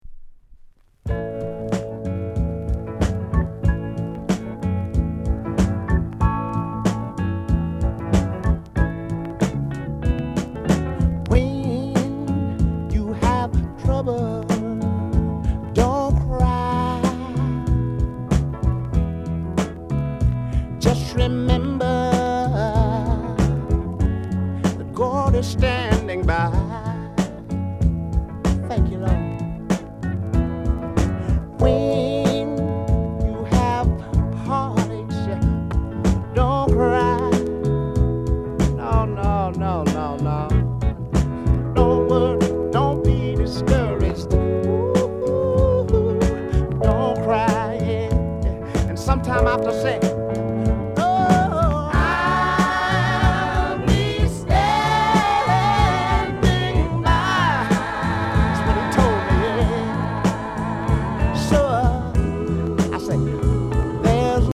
SOUND CONDITION VG
REGGAE